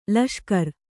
♪ laṣkar